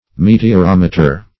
meteorometer - definition of meteorometer - synonyms, pronunciation, spelling from Free Dictionary
Search Result for " meteorometer" : The Collaborative International Dictionary of English v.0.48: Meteorometer \Me`te*or*om"e*ter\, n. [Meteor + -meter.]